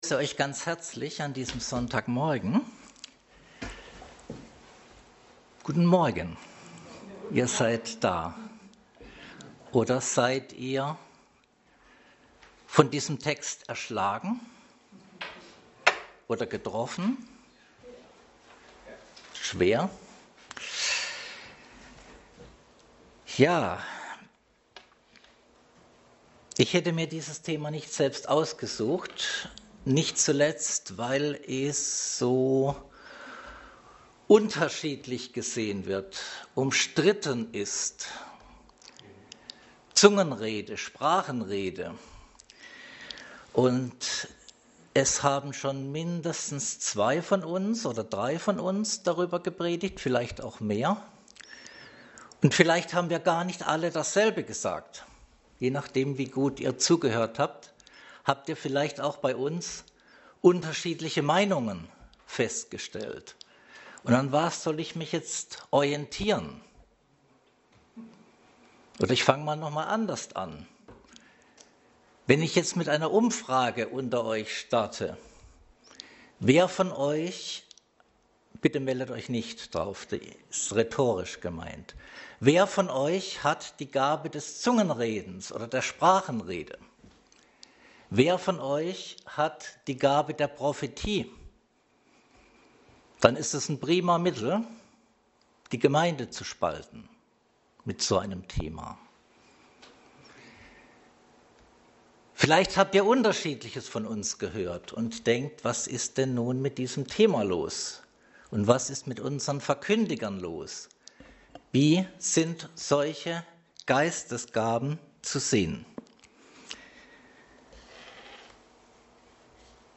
Korinther Passage: 1. Korinther 14,20-25 Dienstart: Predigt Dateien zum Herunterladen Notizen Themen: Gemeinde , Prophetie , Sprachenrede « Die Gemeinde erbauen Göttliche Ordnung oder teuflisches Chaos?